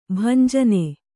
♪ bhanjane